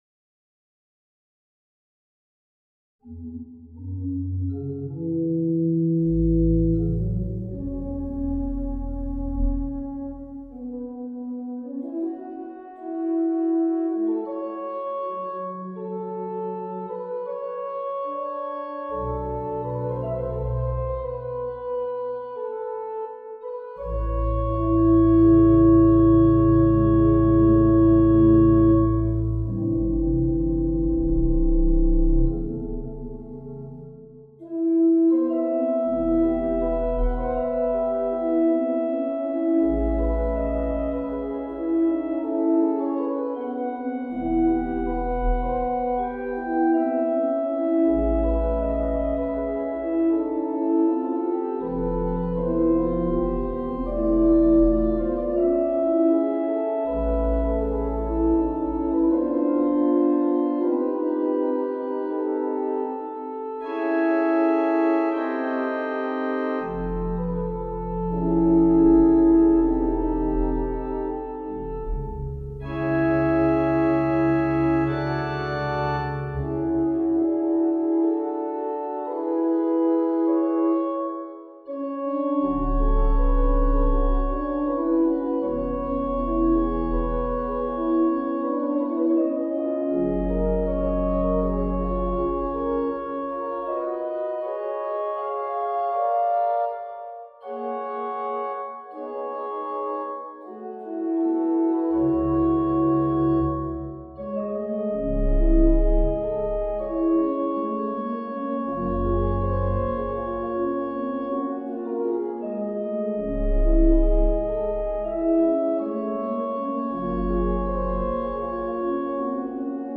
for organ
An opening, quasi improviso, leads to a long-lined melody over slowly changing harmonies across changing meters and with gentle punctuation in the pedal. Smaller voiced registrations are intended throughout.
The lyric opening moves to a more rhythmic 6/8 fugue rooted on F sharp minor. The accompanying counterpoint has a moment to act as its own imitative moment, as duple is contrasted with triple groupings.